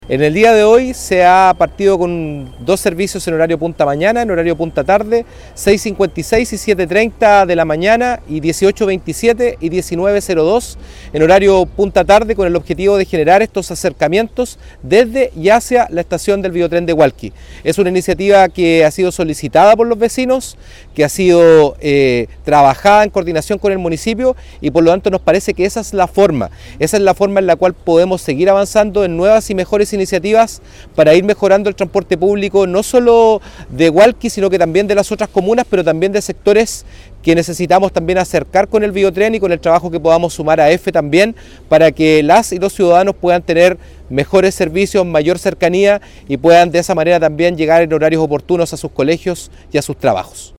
Junto con destacar la labor del municipio al acoger “una petición que han hecho los vecinos frente a una necesidad”, el seremi Fierro entregó más detalles acerca del nuevo servicio y sostuvo que “esa es la forma en la cual podemos seguir avanzando en nuevas y mejores iniciativas para el transporte público, no sólo de Hualqui, sino que también de las otras comunas”.